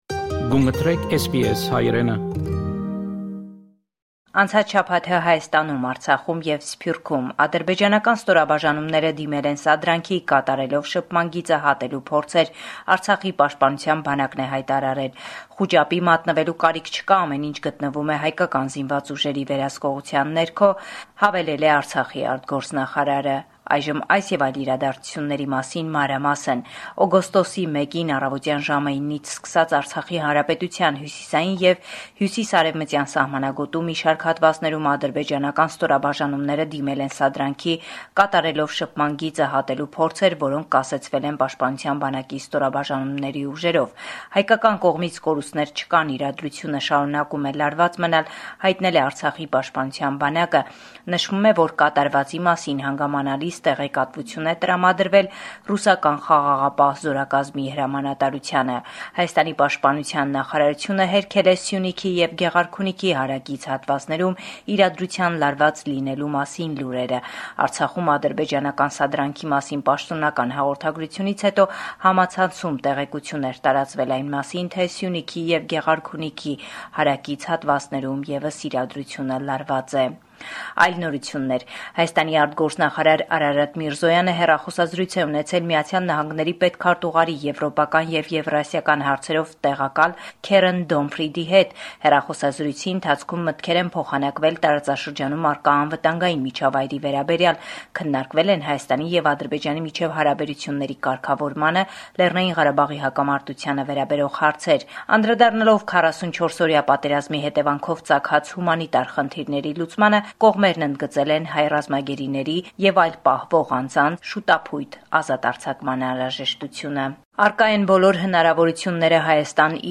Latest News